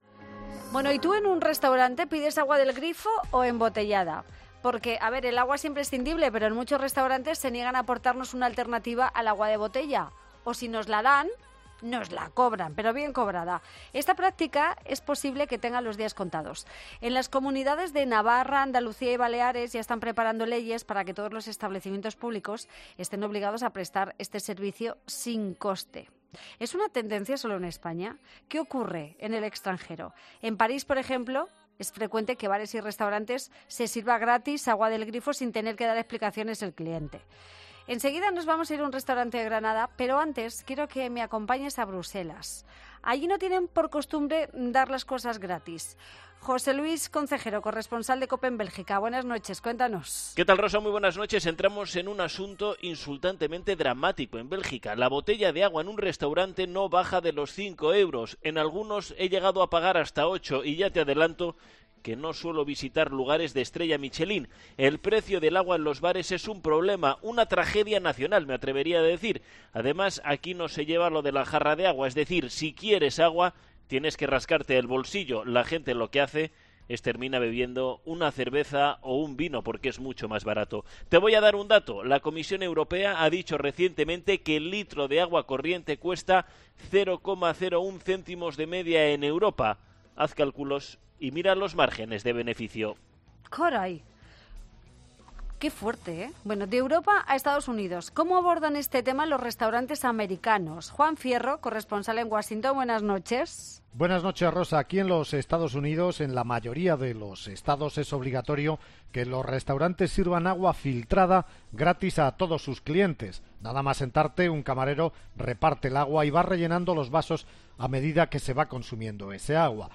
ESCUCHA EL DEBATE COMPLETO SOBRE EL AGUA EN 'LA NOCHE DE COPE CON ROSA ROSADO'